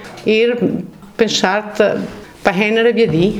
- mòcheno di Roveda (2015);